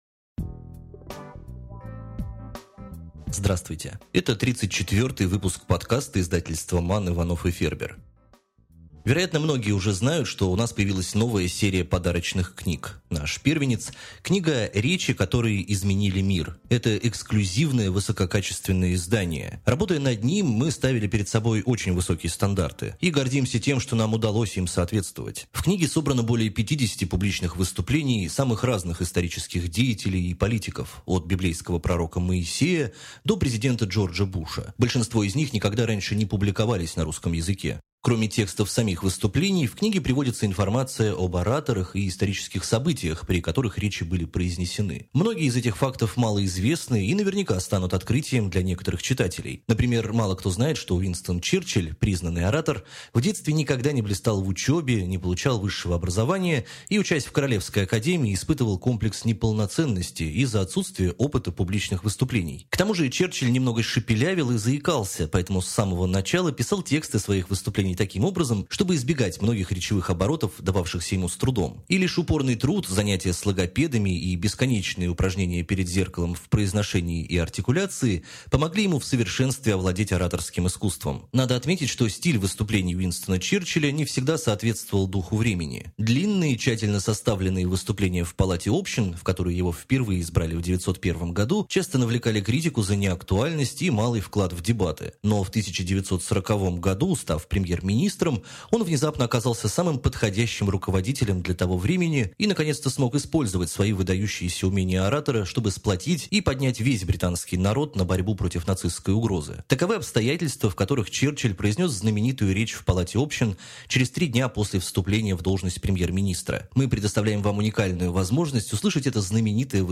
В 34 выпуске подкаста мы расскажем про жемчужину нашей книжной коллекции – книгу «Речи, которые изменили мир». В подкасте приведен фрагмент речи Уинстона Черчиля.
Речь Черчиля